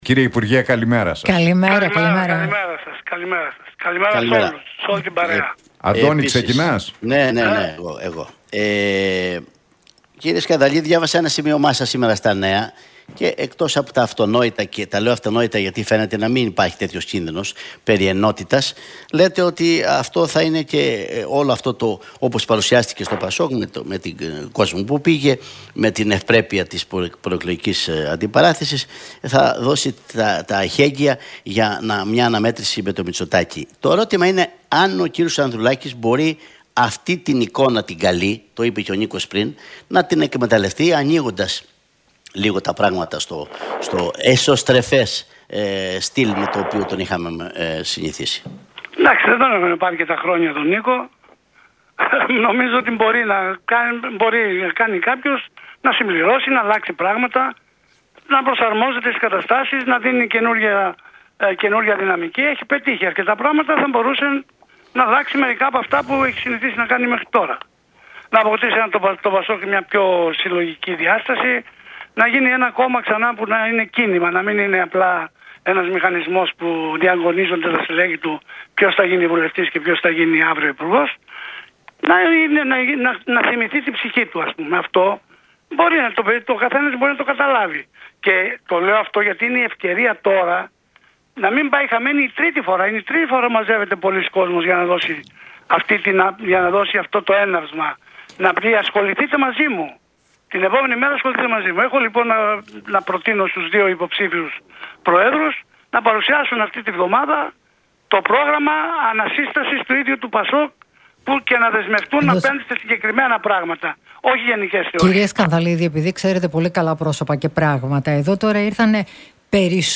Σκανδαλίδης στον Realfm 97,8: Το ΠΑΣΟΚ να θυμηθεί την ψυχή του – Να μην πάει χαμένη η τρίτη φορά